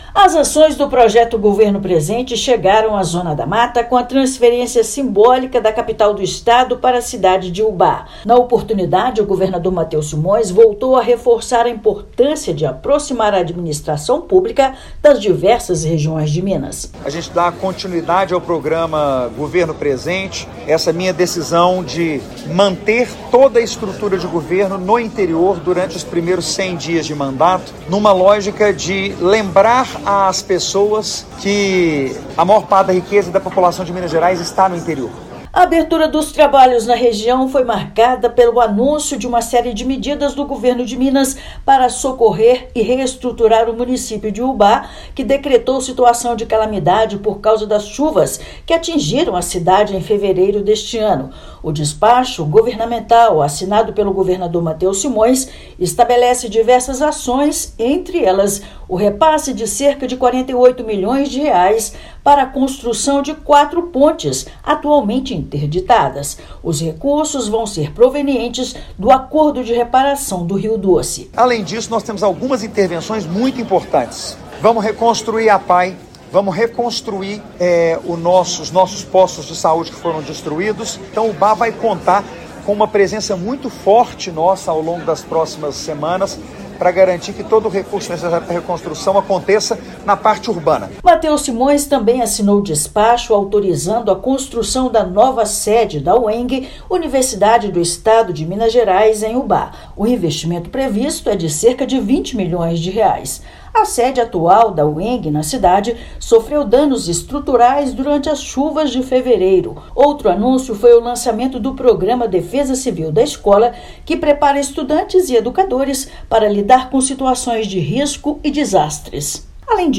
[RÁDIO] Governo de Minas transfere a capital para Ubá e anuncia pacote de investimentos para a infraestrutura da cidade
Reconstrução de pontes, nova sede da Uemg e aquisição de veículos para a Defesa Civil são algumas das medidas divulgadas para socorrer a cidade, que decretou estado de calamidade em decorrência das chuvas. Ouça matéria de rádio.